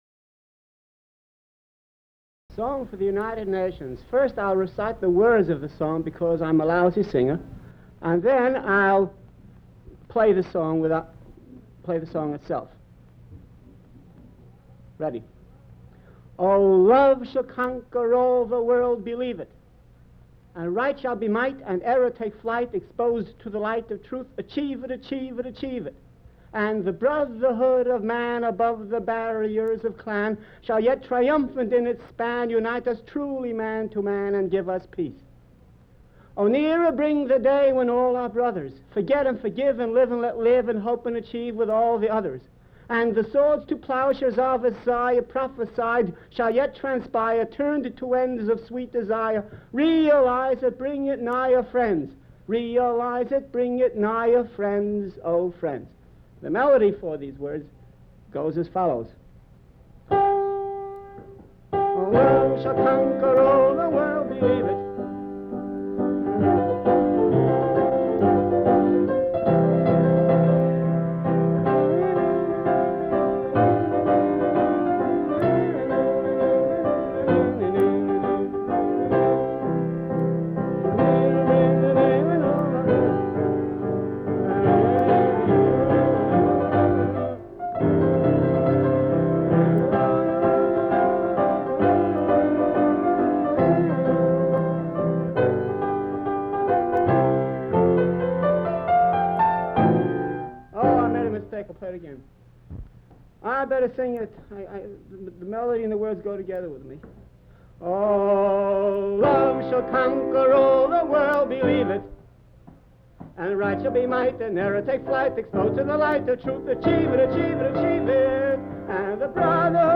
Unidentified man rehearses his song for the United Nations
He recites the lyrics, then plays the melody on the piano, then sings with the piano melody.